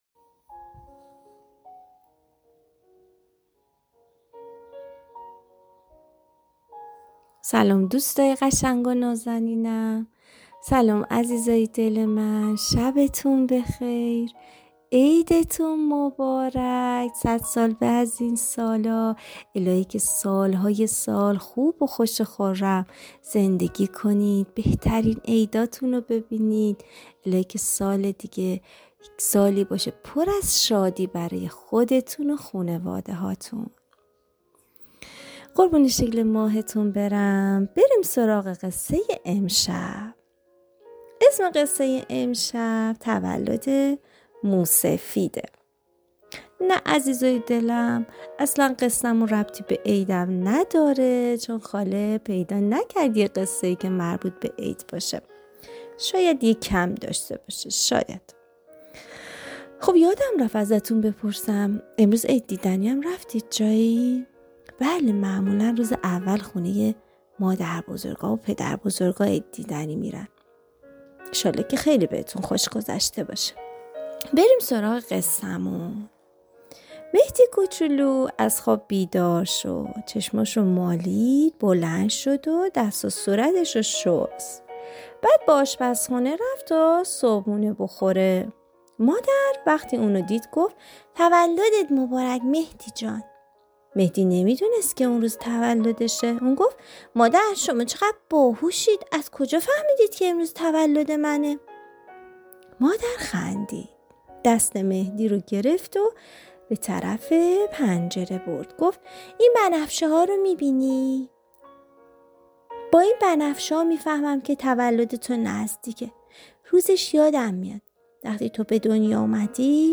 قصه های صوتی
قصه صوتی کودکان دیدگاه شما 121 بازدید